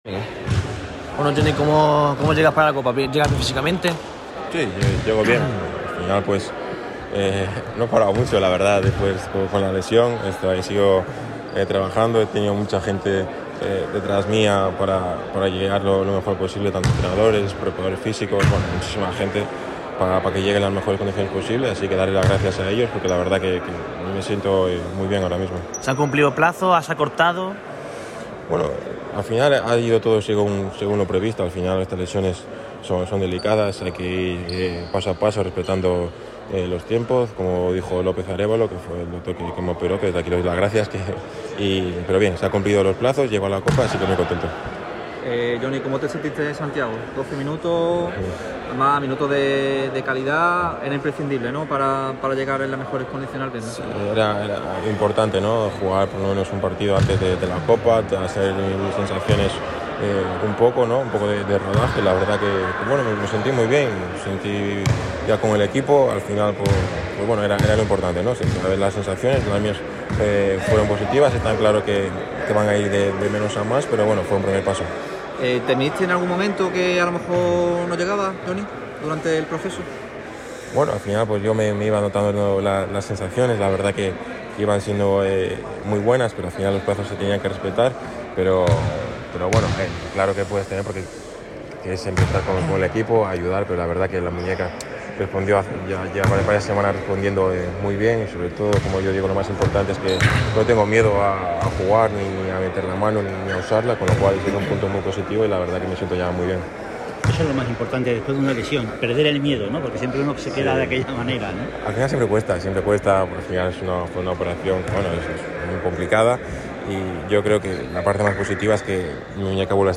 Jonathan Barreiro, alero del Unicaja, asegura que partir como favoritos en la Copa del Rey sería un grandísimo error. El gallego atendió al micrófono rojo en el Media Day de la Copa del Rey, que se celebrará en Málaga el próximo fin de semana.